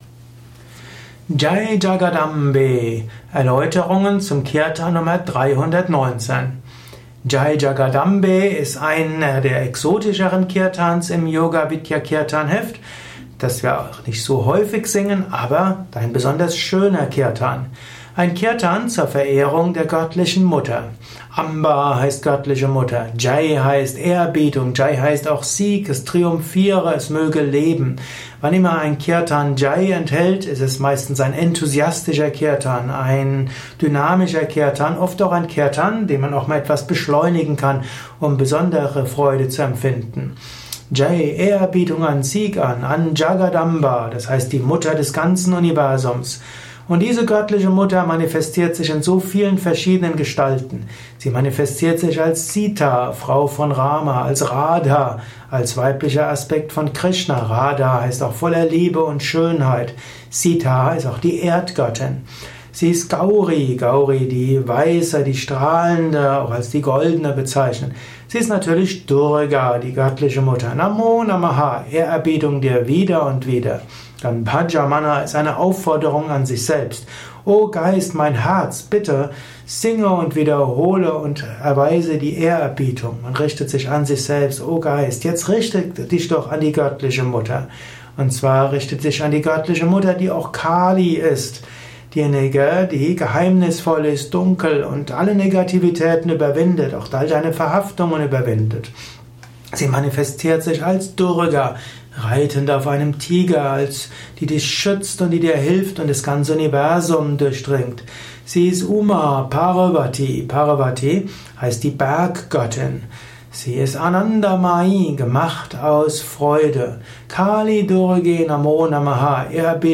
Ein mp3 Audio Kurzvortrag zum Jaya Jagadambe Kirtan , Nummer 319 im
Yoga Vidya Kirtanheft , Tonspur eines Kirtan Lehrvideos.